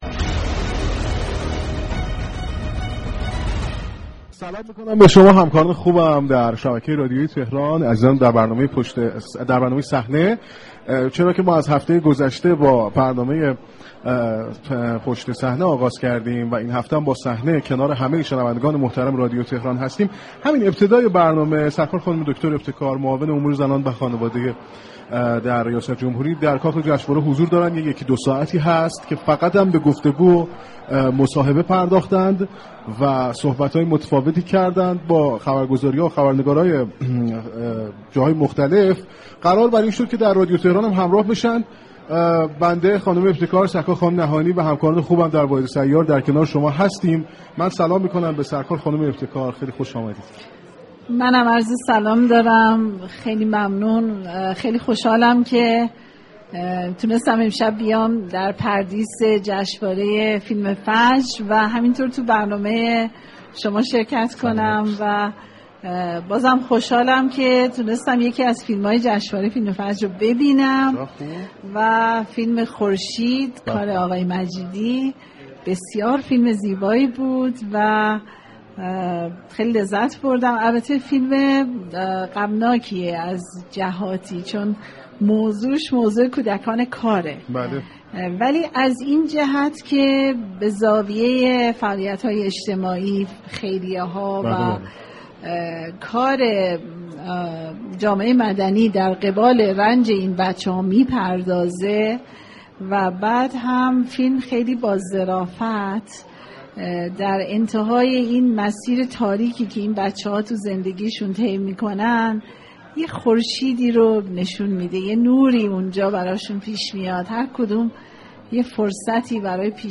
معصومه ابتكار با حضور در پردیس سینمایی ملت و گفتگو با خبرنگاران برنامه‌ی رادیویی صحنه از رادیو تهران، از رونمایی طرح